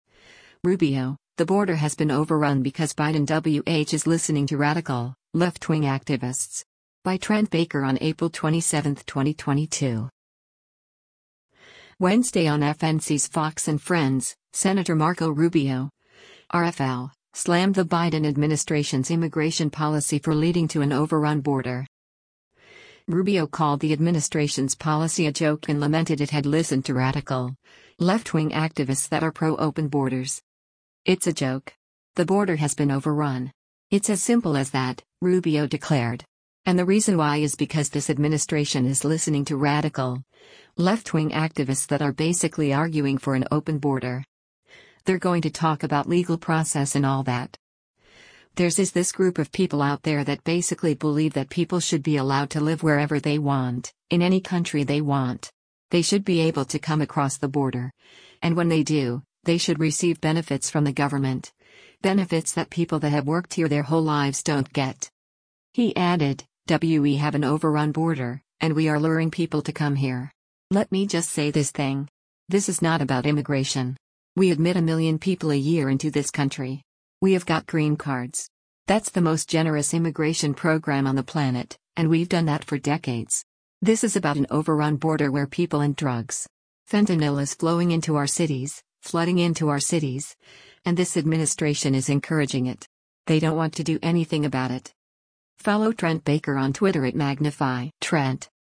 Wednesday on FNC’s “Fox & Friends,” Sen. Marco Rubio (R-FL) slammed the Biden administration’s immigration policy for leading to an “overrun” border.